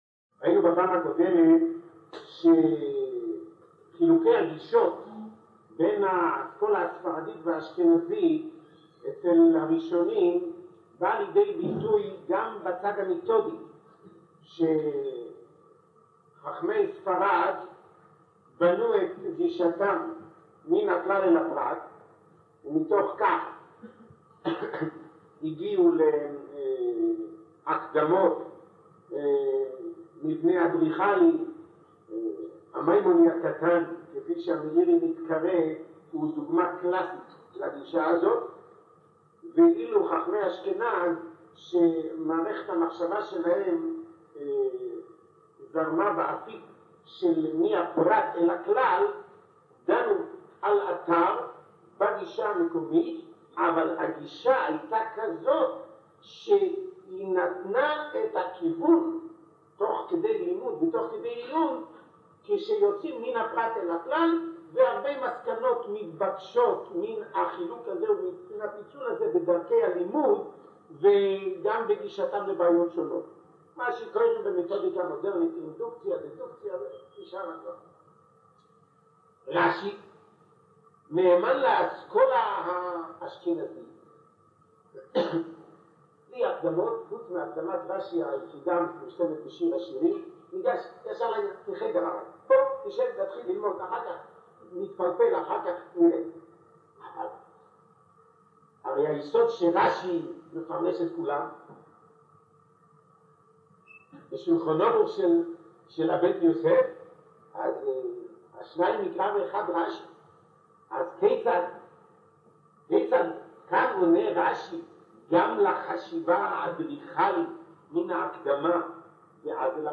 שבט תשע"ז להאזנה לשיעור